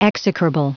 Prononciation du mot execrable en anglais (fichier audio)
Prononciation du mot : execrable
execrable.wav